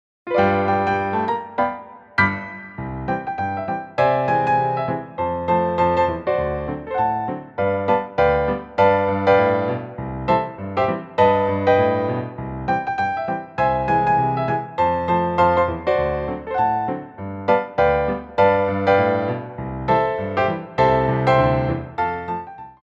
Petit Allegro 2
4/4 (16x8)